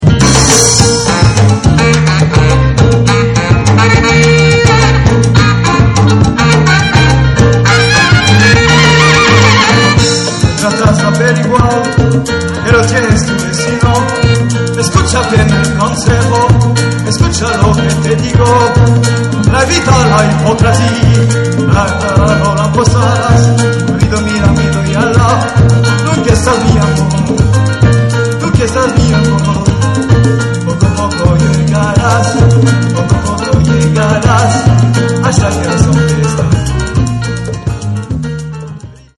Live au Cannet Salsa
Orchestre presque complet.